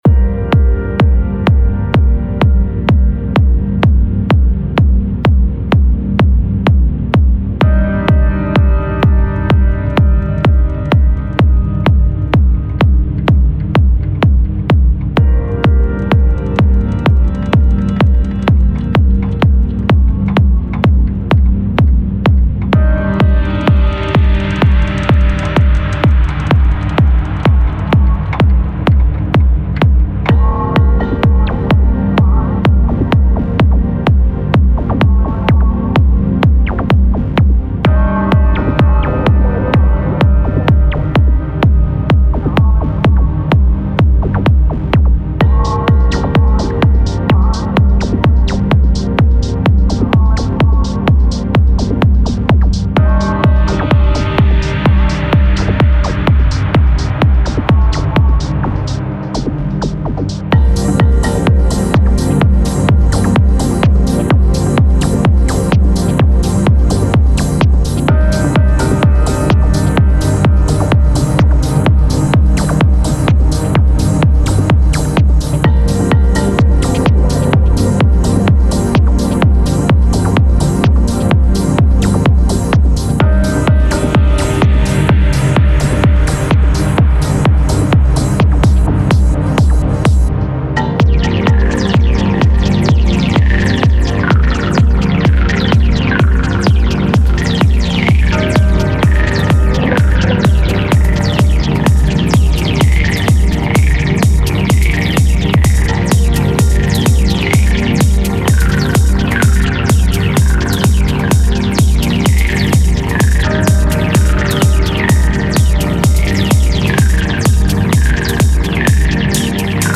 Techno and house music, deep bass and fast beats.